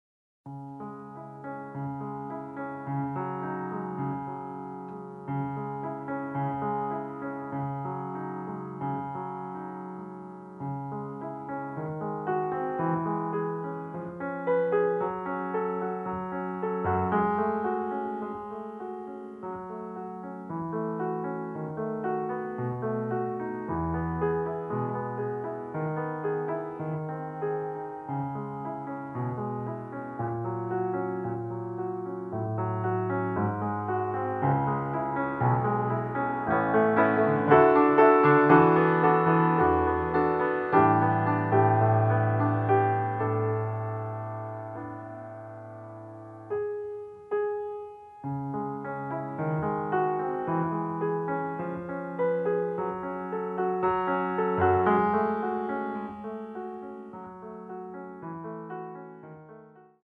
accompaniment excerpt